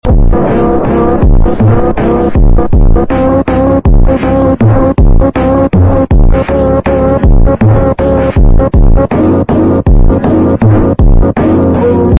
Accidente de tren y choque de tren con el camión